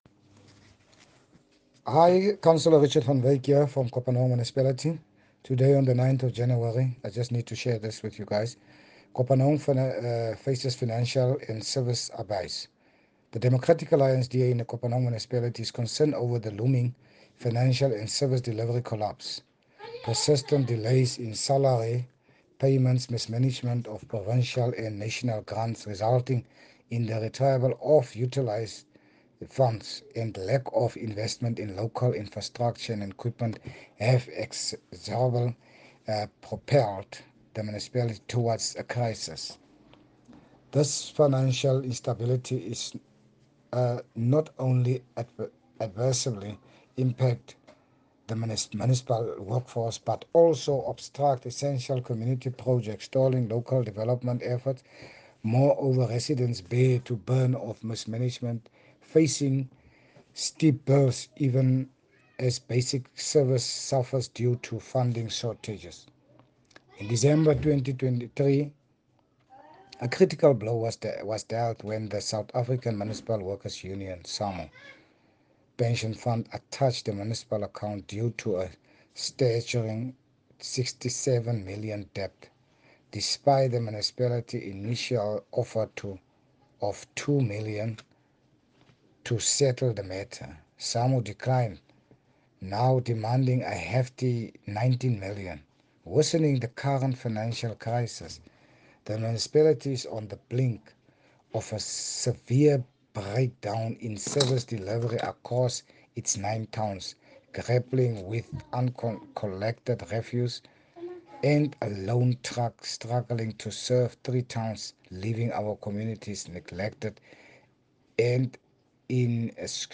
Issued by Richard van Wyk – DA Councillor Kopanong Local Municipality
Afrikaans soundbites by Cllr Richard van Wyk and